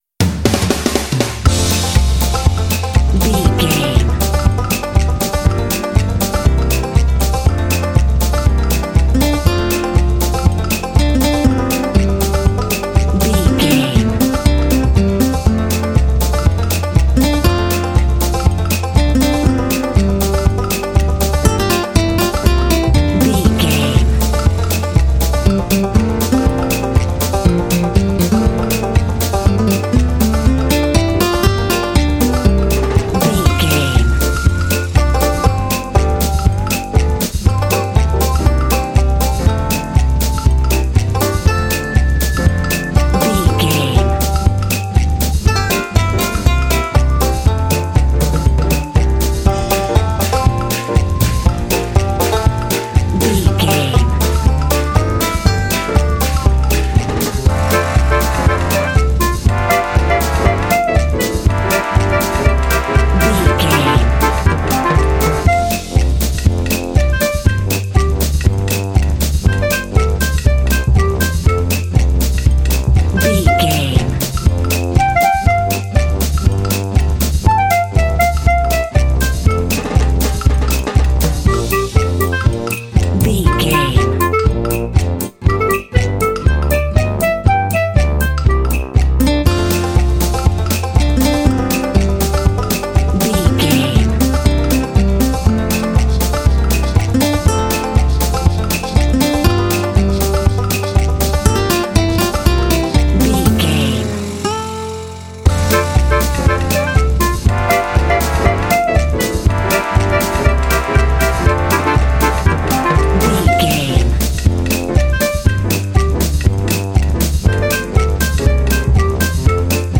Ionian/Major
playful
cheerful/happy
piano
banjo
bass guitar
drums
brass
acoustic guitar
percussion